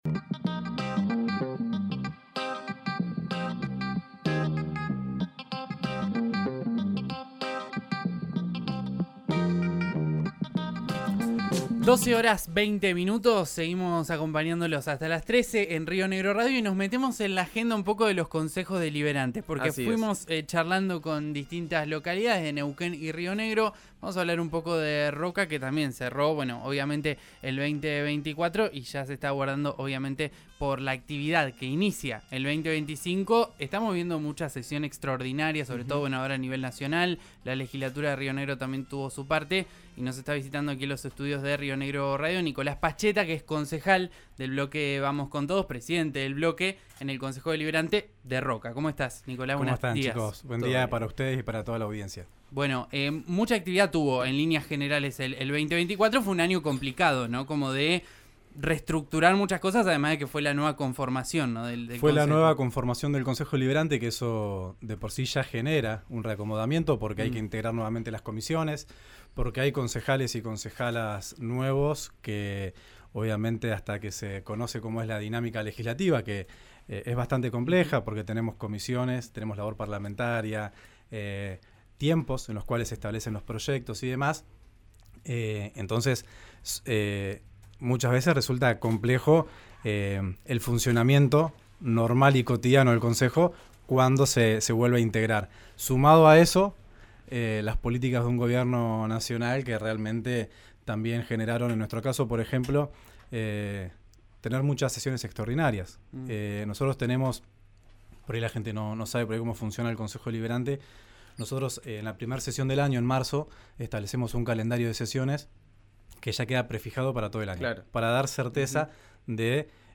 Escuchá a Nicolás Paschetta en RÍO NEGRO RADIO: